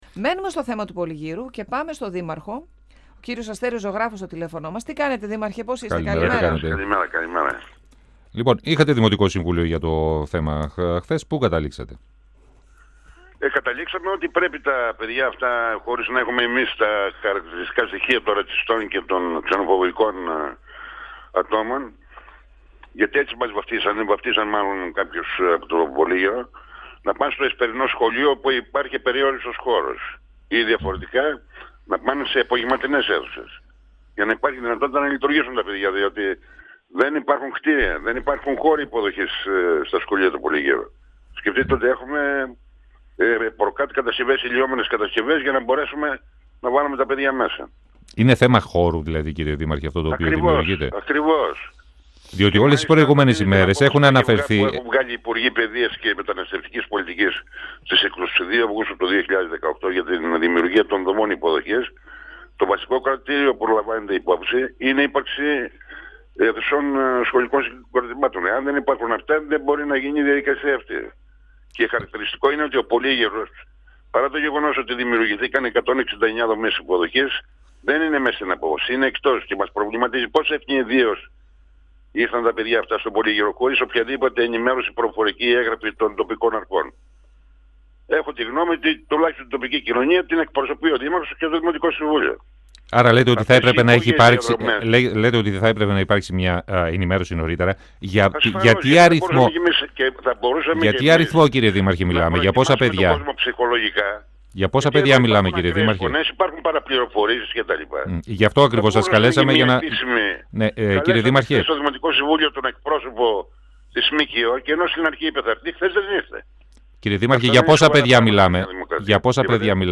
Στην κατάσταση που επικρατεί στα σχολεία, στην αδυναμία να ενταχθούν περισσότεροι μαθητές στις τάξεις και στην απουσία ενημέρωσης για την εγκατάσταση προσφύγων στον Πολύγυρο, αναφέρθηκε ο δήμαρχος Αστέριος Ζωγράφος, μιλώντας στον 102FM της ΕΡΤ3. Επίσης ο δήμαρχος Πολυγύρου τάχθηκε υπέρ της δημιουργίας τάξεων υποδοχής σε απογευματινό σχολείο.
Επίσης ο δήμαρχος Πολυγύρου τάχθηκε υπέρ της δημιουργίας τάξεων υποδοχής σε απογευματινό σχολείο. 102FM Συνεντεύξεις ΕΡΤ3